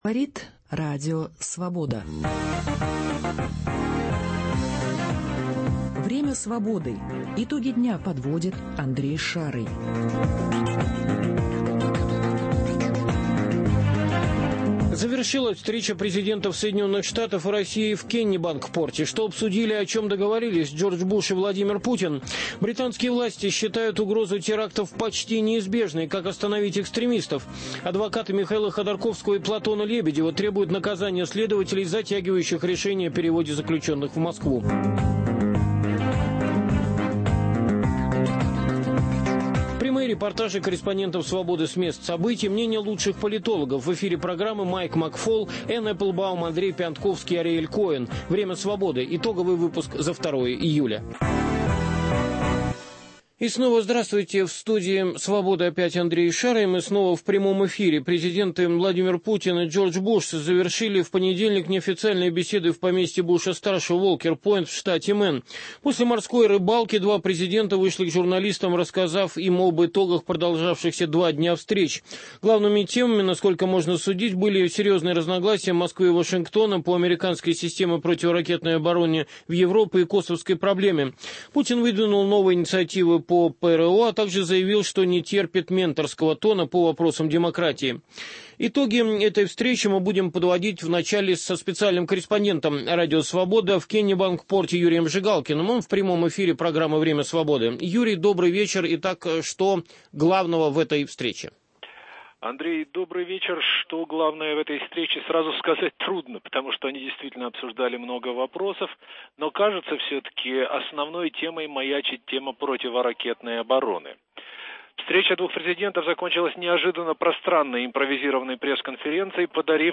Прямые репортажи корреспондентов Свободы с мест событий.